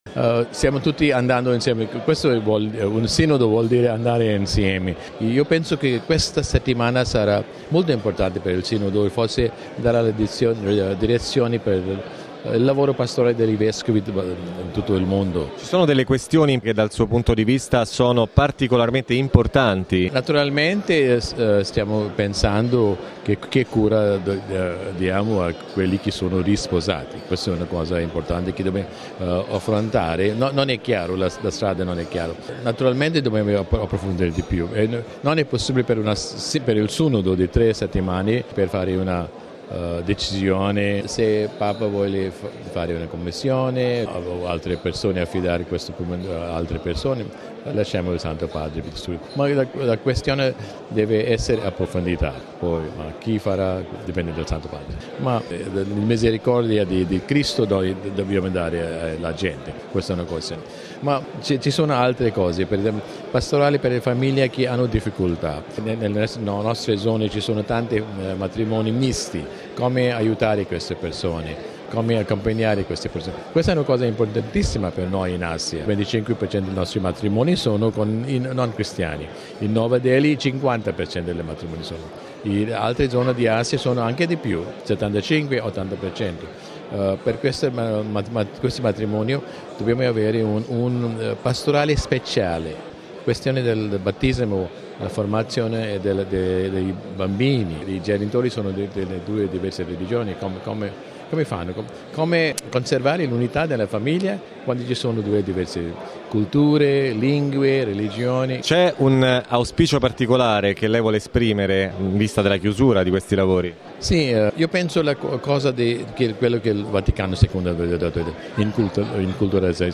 Al Sinodo, la questione dei divorziati risposati è ancora aperta, ma ci sono anche molti altri temi importanti da affrontare: lo afferma il cardinale indiano Oswald Gracias, arcivescovo di Bombay.